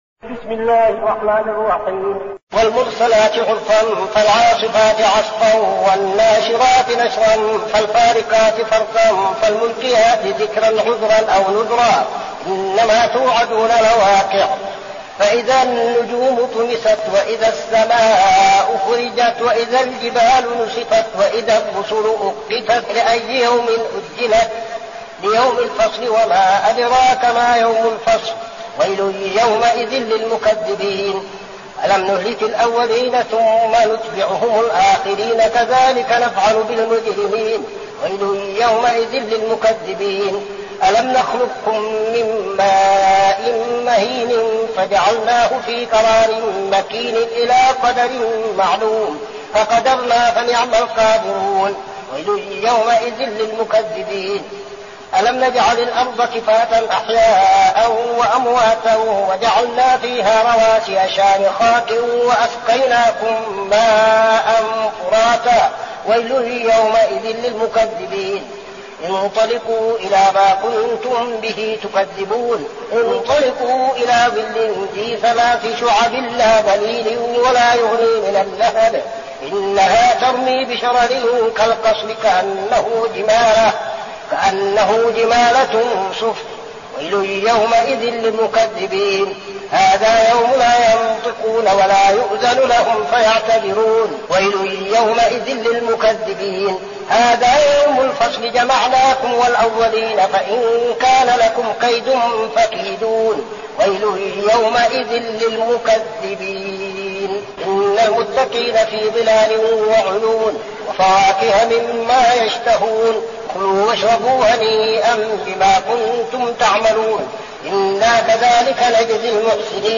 المكان: المسجد النبوي الشيخ: فضيلة الشيخ عبدالعزيز بن صالح فضيلة الشيخ عبدالعزيز بن صالح المرسلات